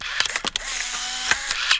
polaroid.wav